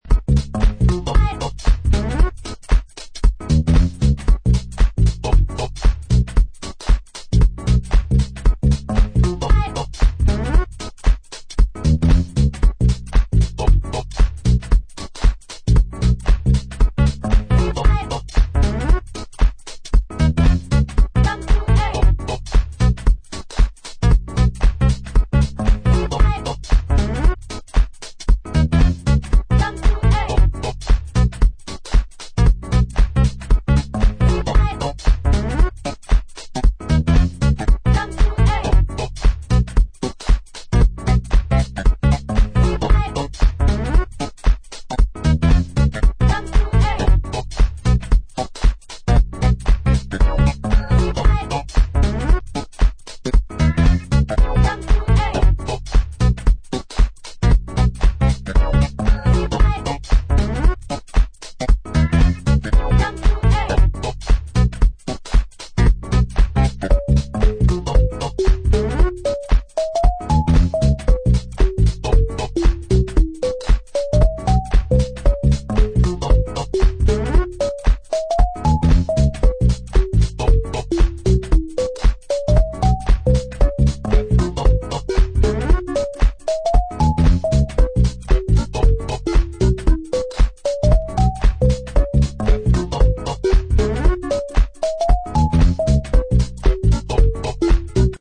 Five tracks of swinging melodic madness